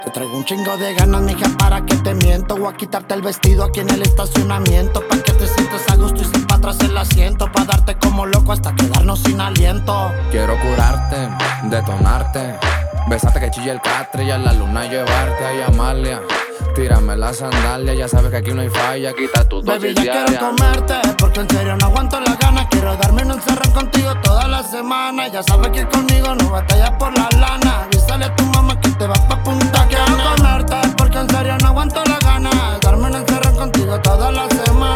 Страстные ритмы латино
Urbano latino
Жанр: Латино